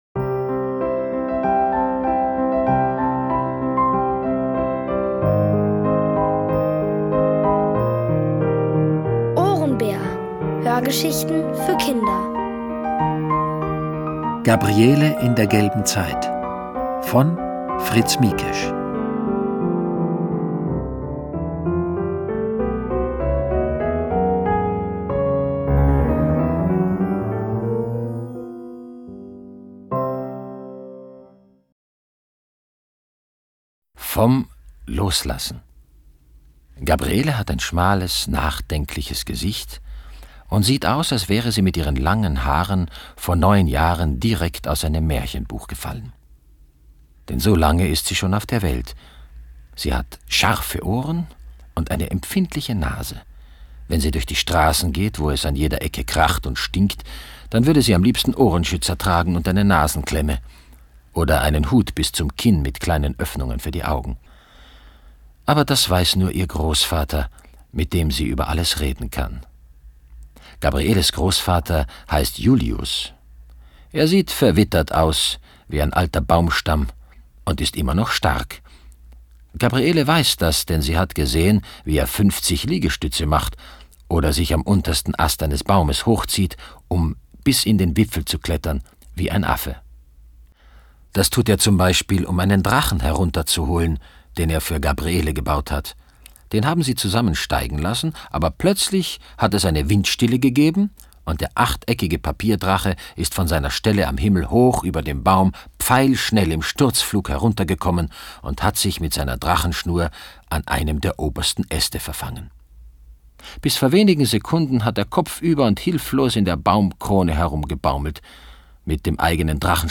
Von Autoren extra für die Reihe geschrieben und von bekannten Schauspielern gelesen.
Es liest: Peter Simonischek.